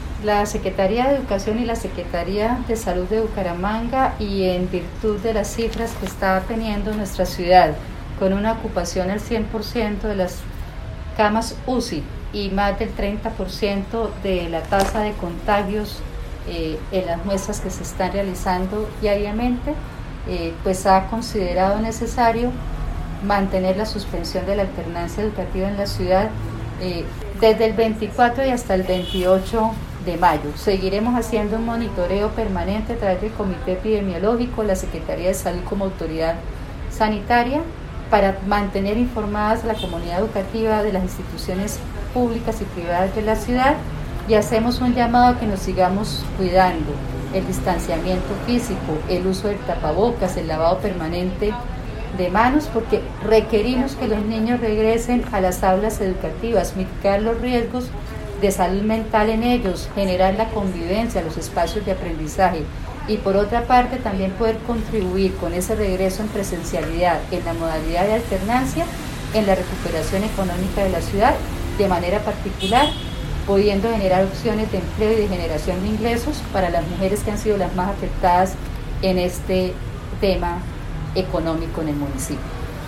Audio: Ana Leonor Rueda, secretaria de Educación